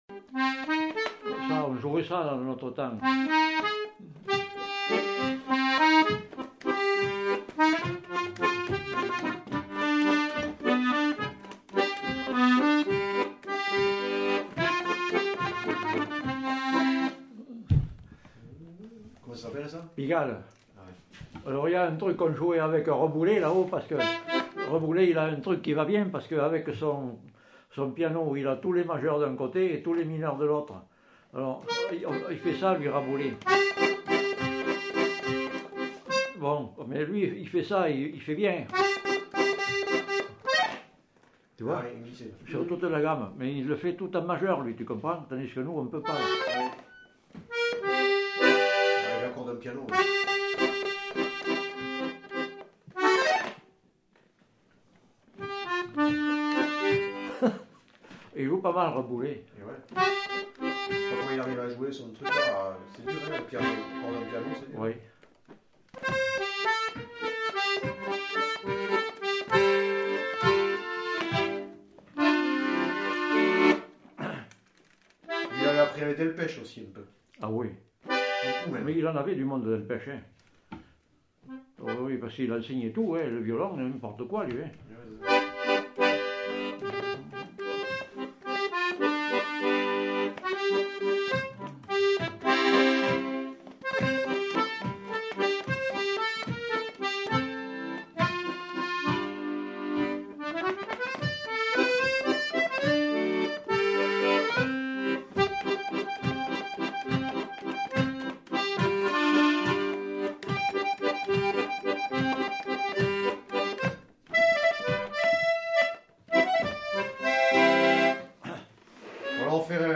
Aire culturelle : Quercy
Lieu : Bétaille
Genre : morceau instrumental
Instrument de musique : accordéon chromatique
Danse : valse